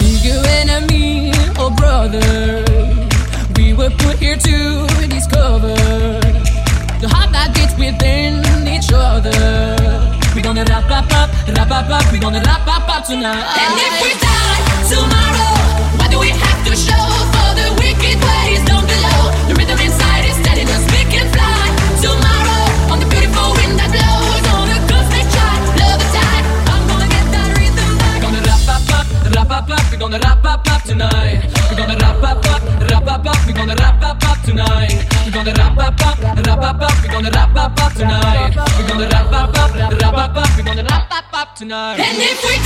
Genere: pop, dance